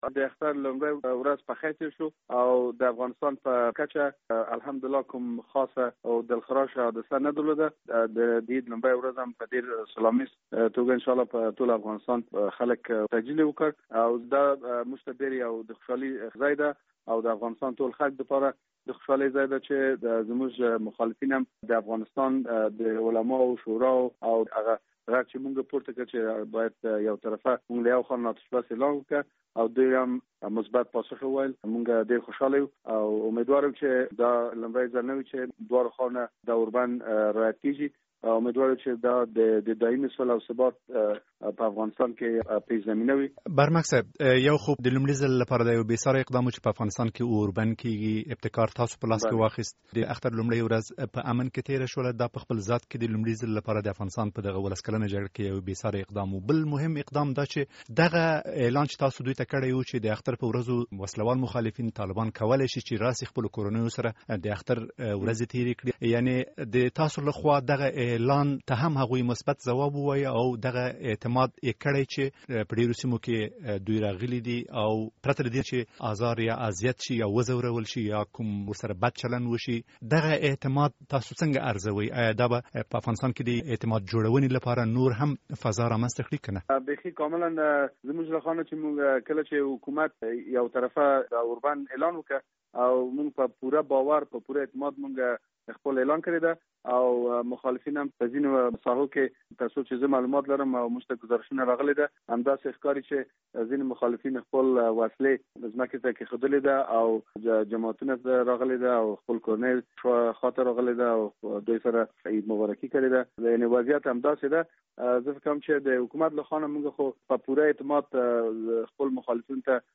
مرکه
ښاغلي ویس احمد برمک سره مرکه